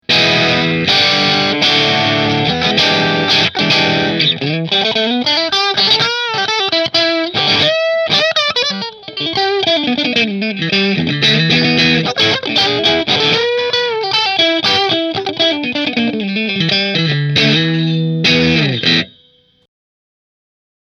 • HSS Kent Armstrong Pickup Configuration
Rahan Guitars RP Double Cutaway Figured Maple Position 4 Through Marshall